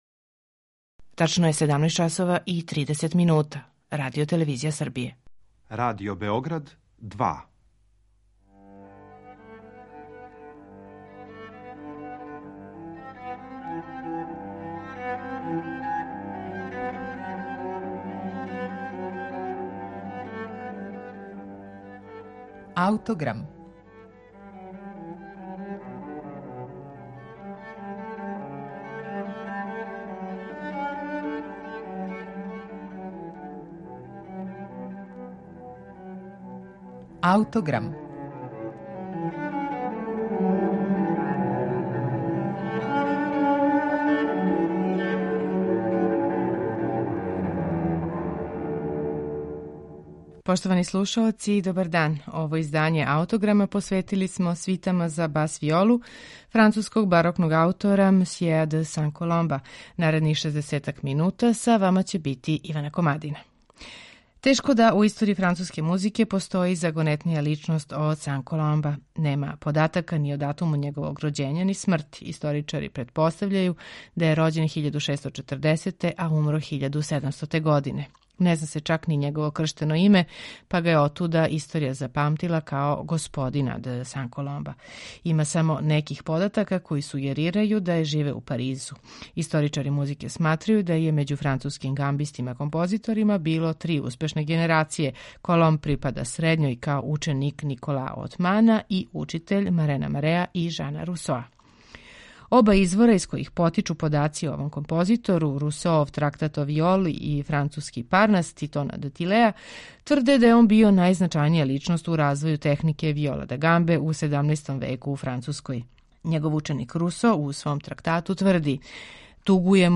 Данашњи Аутограм посветили смо свитама за бас-виолу овог француског барокног мајстора. Слушаћете их у интерпретацији Жордија Саваља, који их свира на виоли са седам жица.